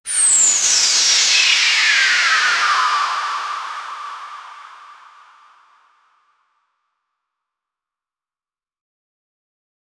win-sound-effect--ne5n3fbq.wav